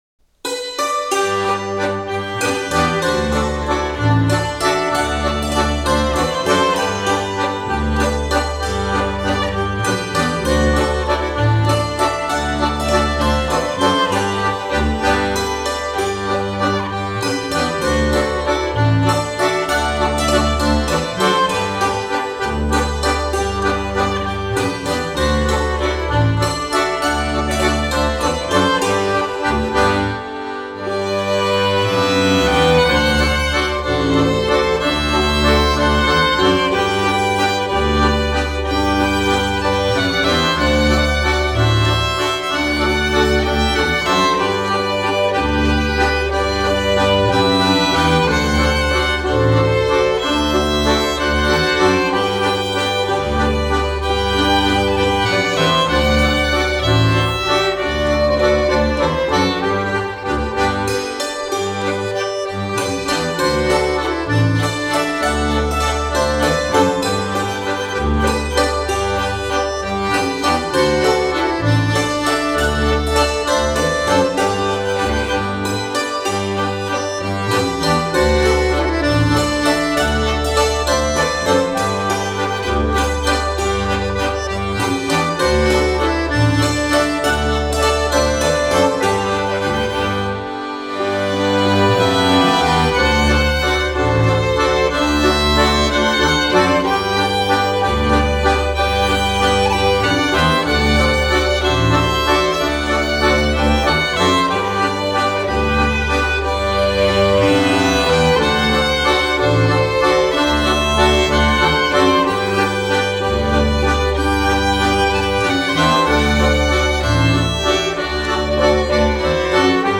Reit Musig: Tessiner Wälserli “Spunta il sol”